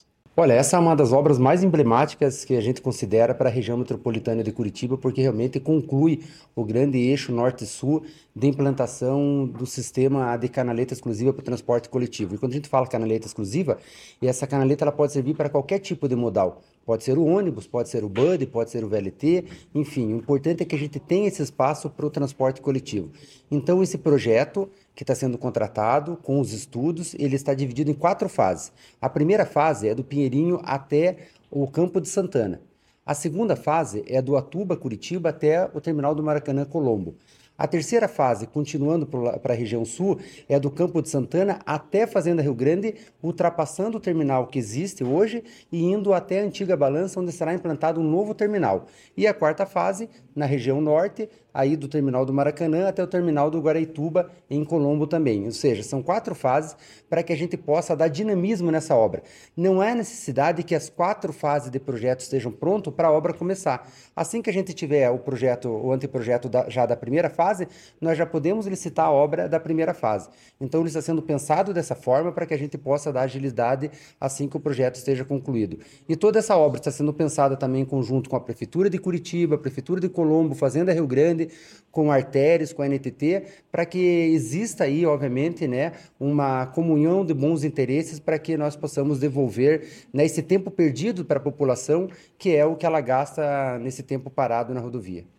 Sonora do diretor-presidente da Amep, Gilson Santos, sobre o anúncio de corredor de ônibus para conectar Colombo, Curitiba e Fazenda Rio Grande